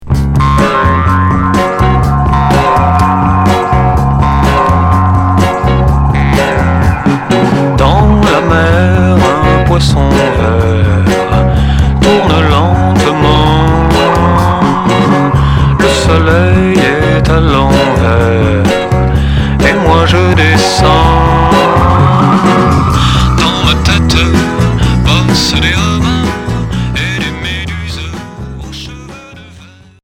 Chanteur 60's Deuxième 45t retour à l'accueil